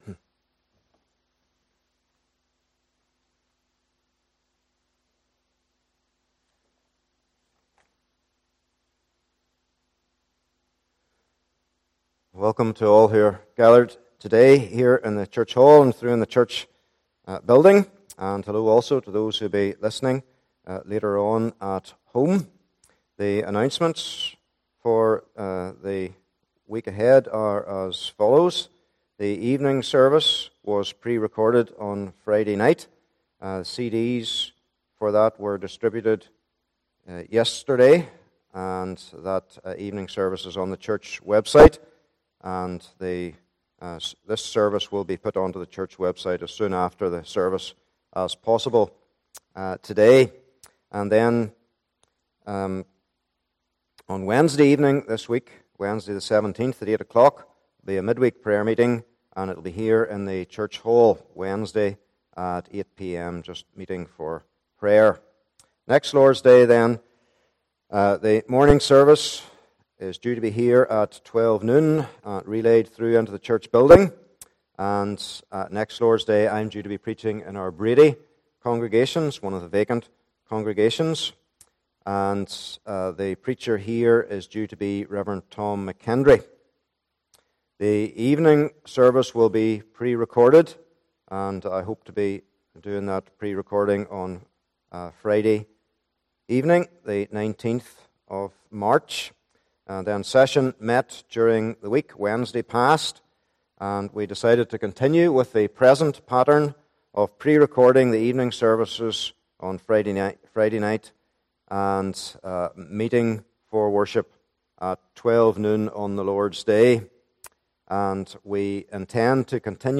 1 Corinthians Passage: 1 Corinthians 3 : 10 - 17 Service Type: Morning Service « Trouble Turned To Good Though I Was Blind